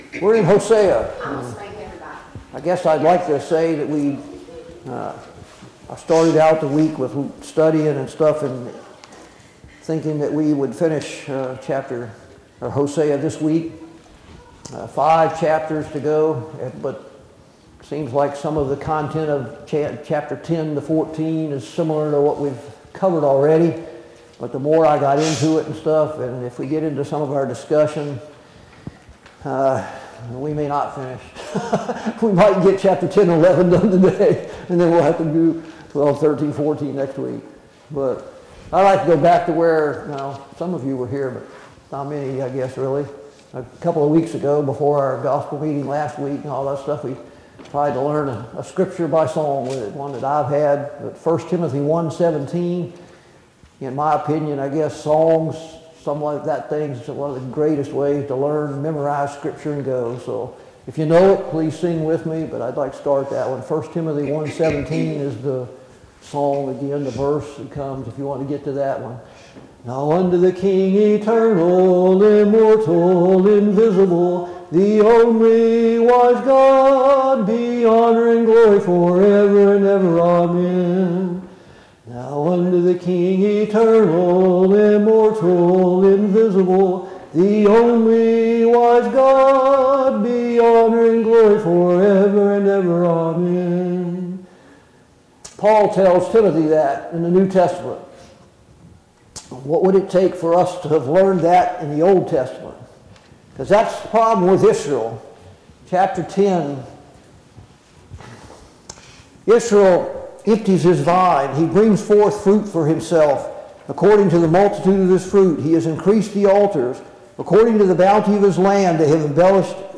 Study on the Minor Prophets Passage: Hosea 10-12 Service Type: Sunday Morning Bible Class « 5.